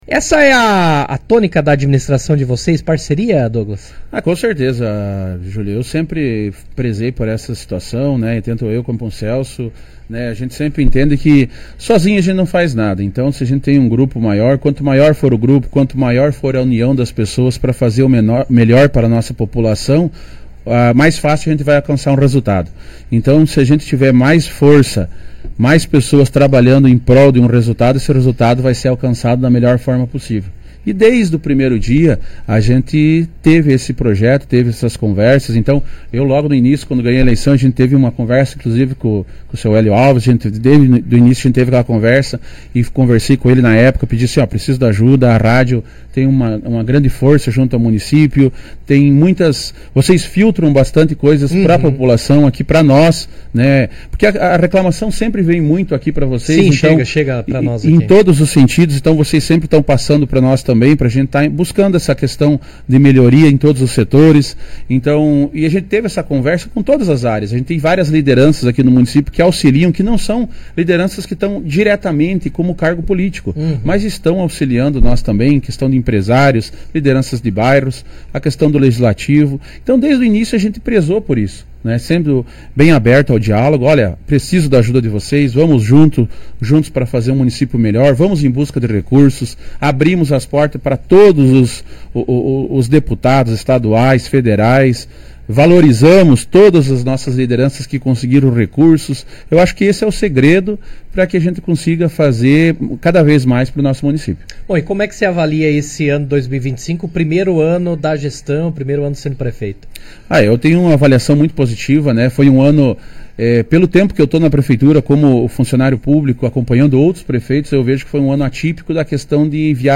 Durante participação no Jornal RA 2ª Edição desta quarta-feira, 31, o prefeito Douglas Potrich apresentou um balanço detalhado das ações desenvolvidas ao longo de 2025, destacou obras em andamento e anunciou projetos estratégicos para 2026, além de abordar desafios enfrentados pela administração municipal, com ênfase na manutenção de estradas rurais e na coleta de lixo.